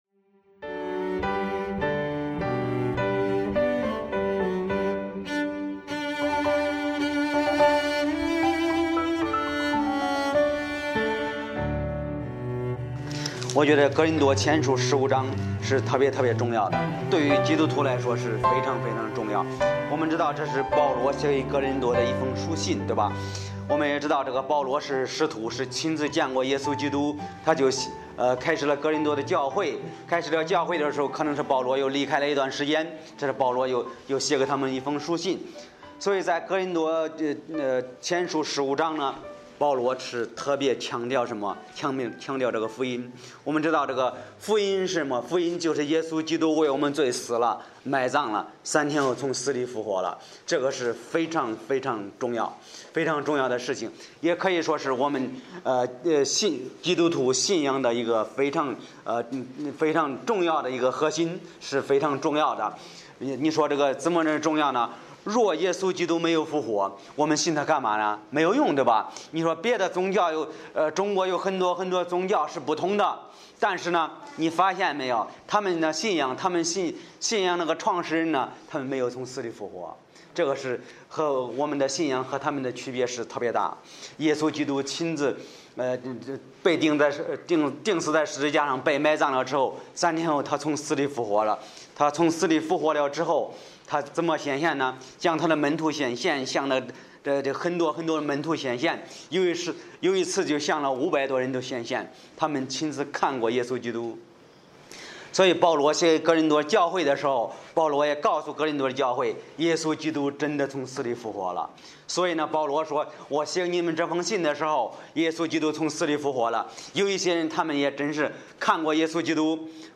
讲道者